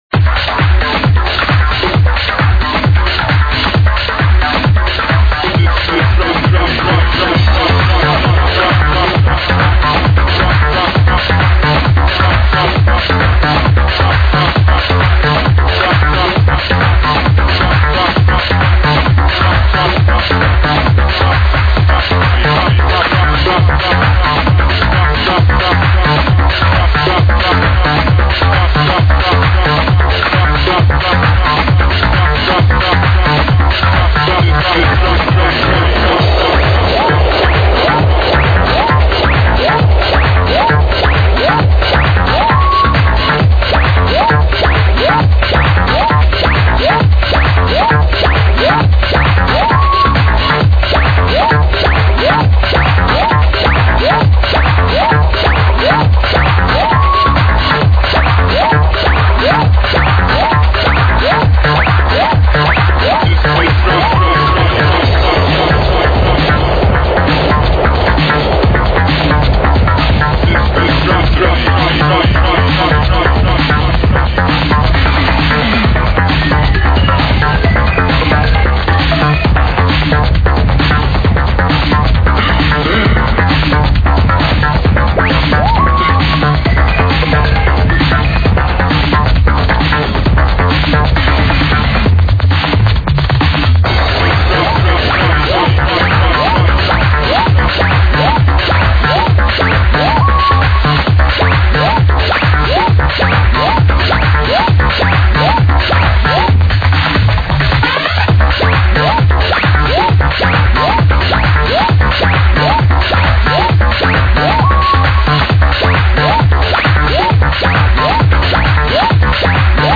<--- from this liveset...